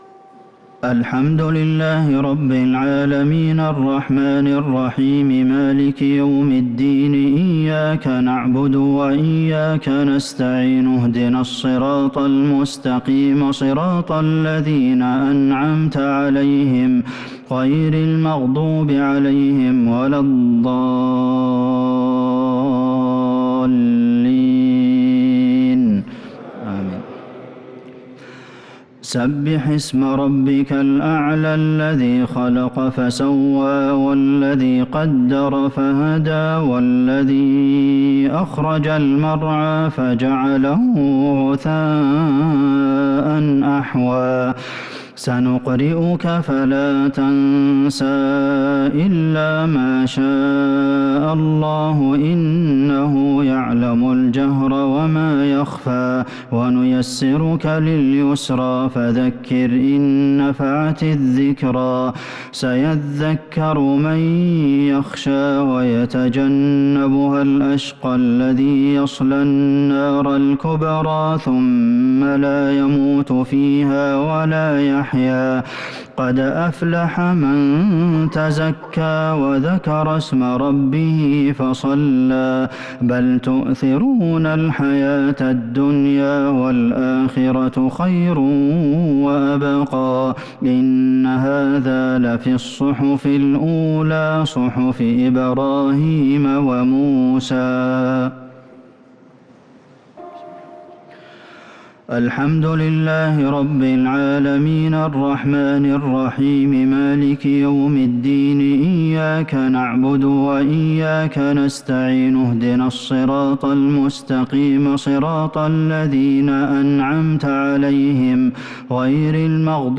الشفع و الوتر ليلة 14 رمضان 1443هـ | Witr 14 st night Ramadan 1443H > تراويح الحرم النبوي عام 1443 🕌 > التراويح - تلاوات الحرمين